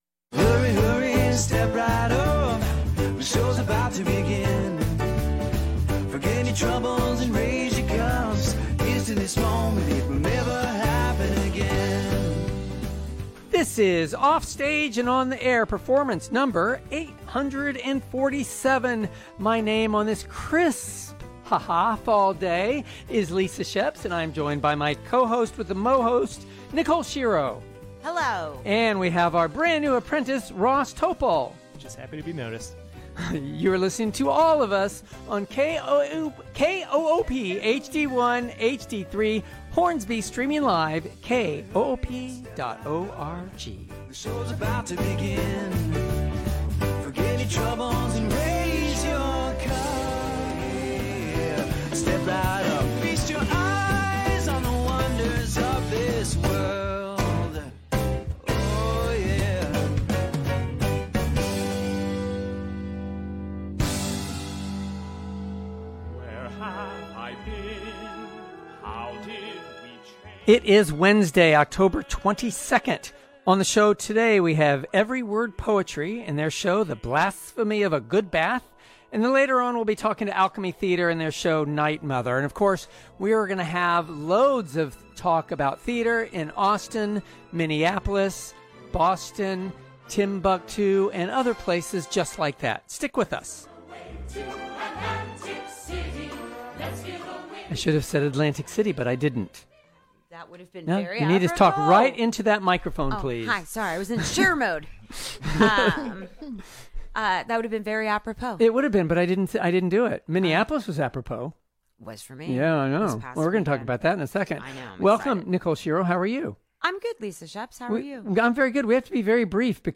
A lively and fun conversation about Theatre around the country and the local Austin Theatre Sce ne